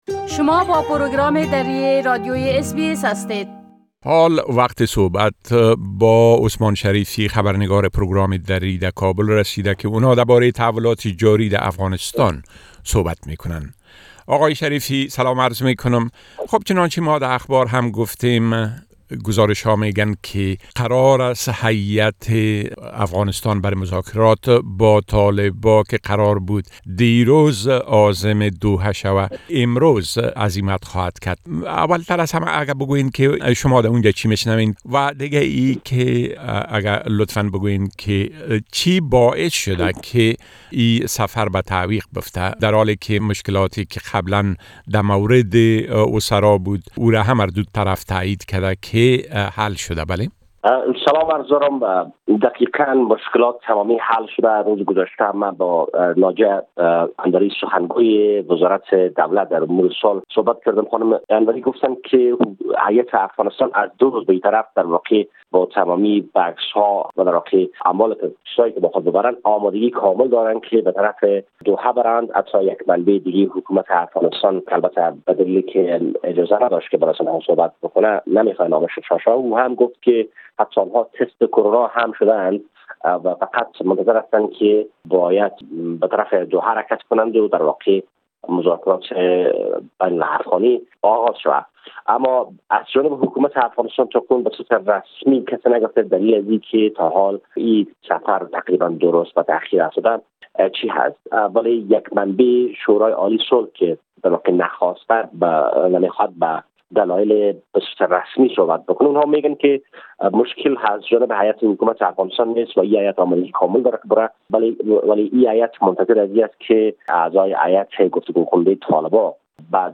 گزارش كامل خبرنگار ما در كابل بشمول اوضاع امنيتى و تحولات مهم ديگر در افغانستان را در اينجا شنيده ميتوانيد.